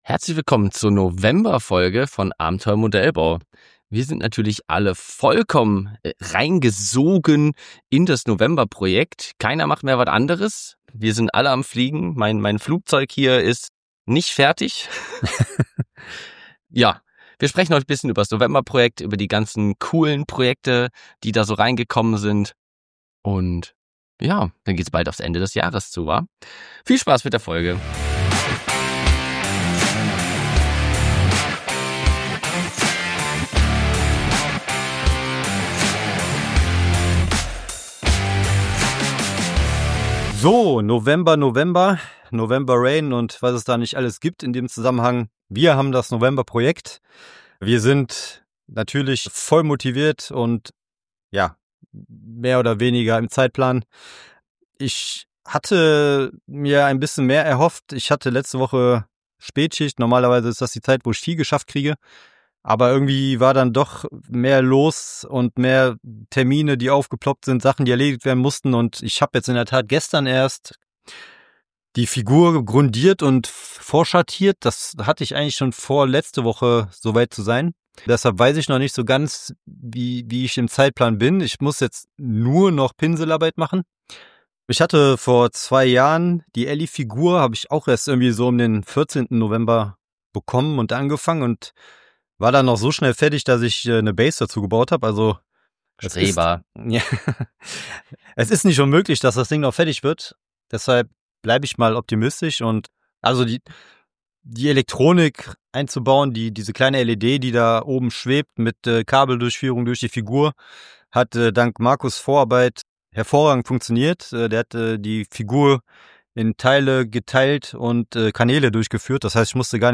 Und darum sitzen wir einen Tag nach der Ausstellung vor den Mikrophonen und lassen das vergangene Wochenende Revue passieren.